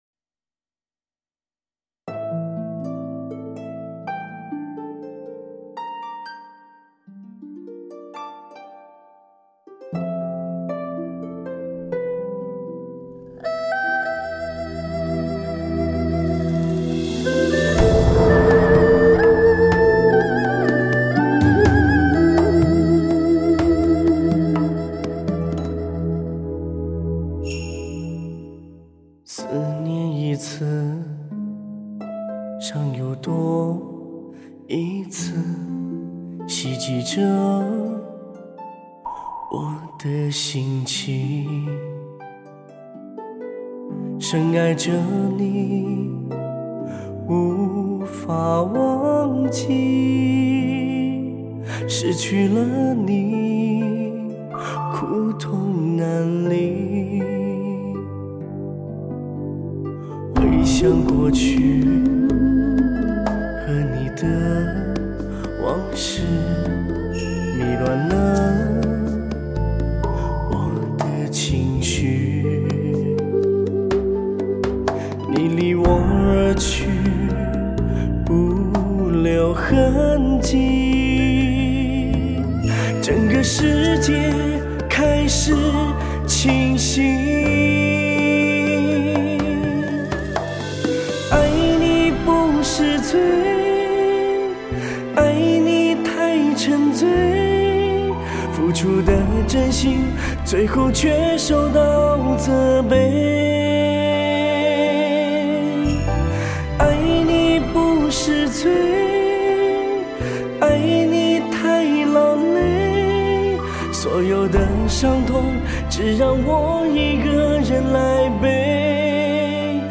兼容黑胶的高保真和CD的低噪音
CD6 伤感情歌